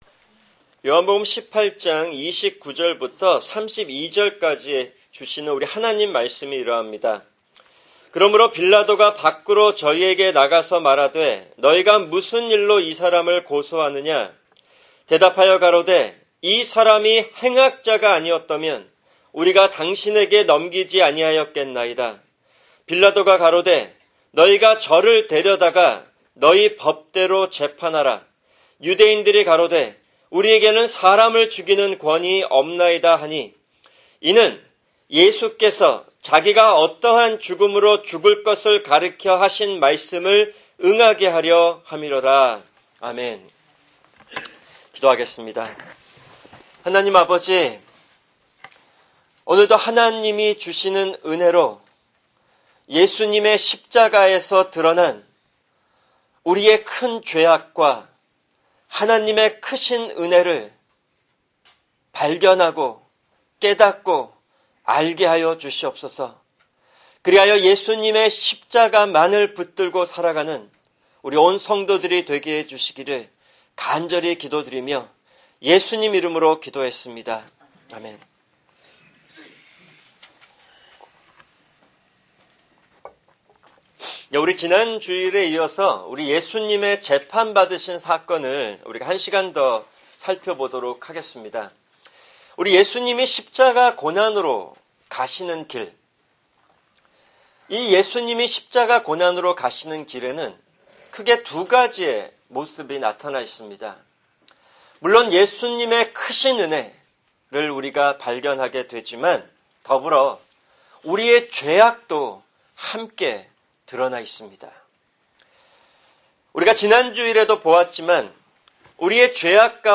[주일 설교] 요한복음 18:12-32(2)